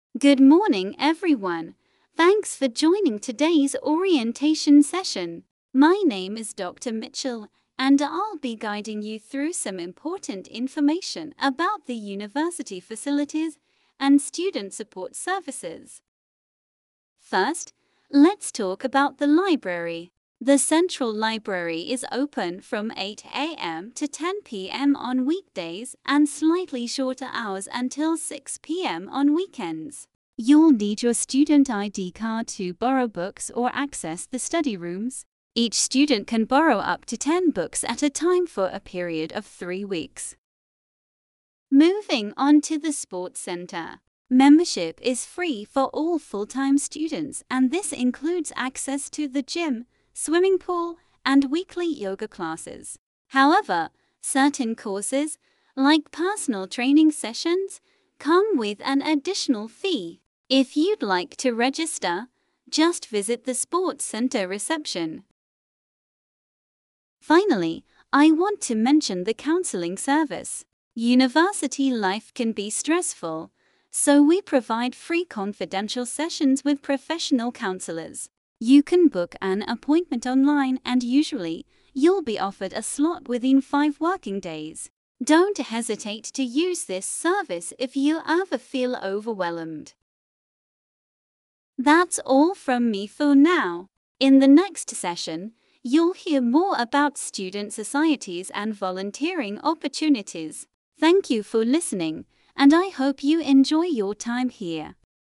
University-Student-Meeting-IELTS.mp3